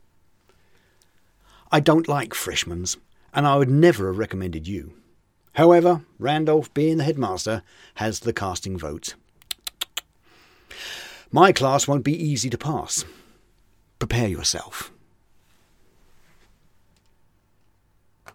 Voice: Any pitch (preferably medium), strict, maybe a bit cold.
Accent: A British accent is required.
• male adult
• british